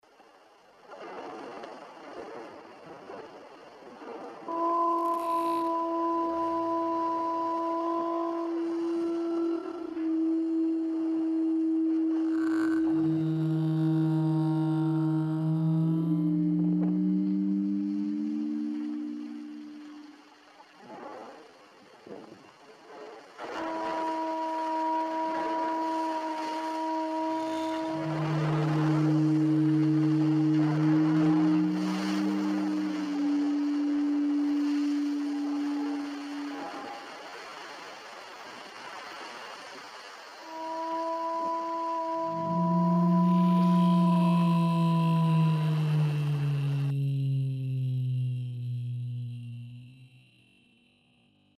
sings the haunting siren call
one of the main musical themes of the film